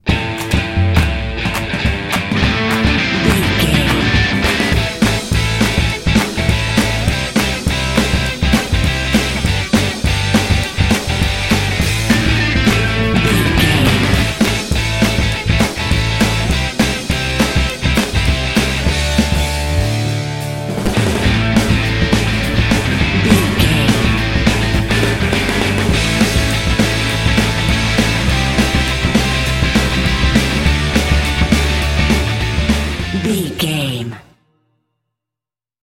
This cool rock track is suitable for lively sport games.
Aeolian/Minor
powerful
energetic
heavy
drums
bass guitar
electric guitar
rock
indie
alternative